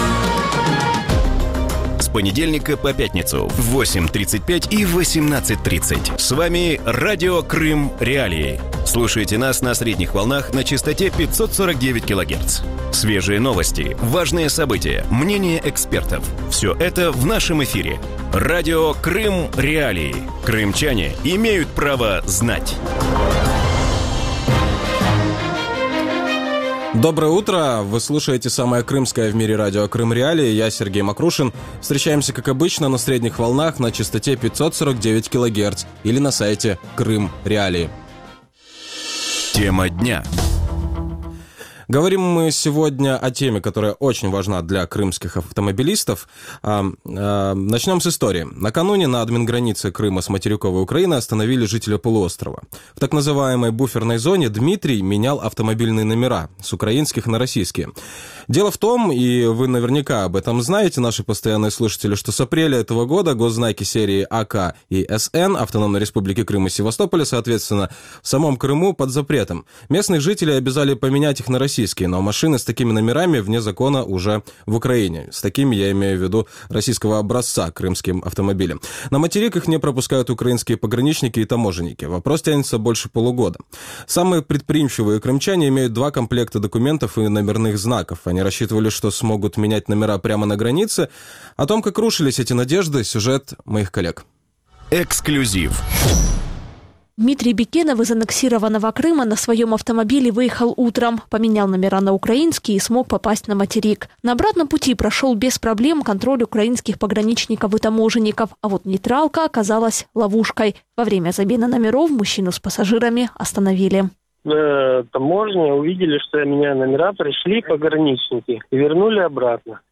Утром в эфире Радио Крым.Реалии обсуждают скандалы на административной границе Крыма с Херсонской областью. За последнюю неделю украинские силовики по несколько часов разбирались с крымскими водителями, которые меняли на «нейтральной полосе» автомобильные знаки.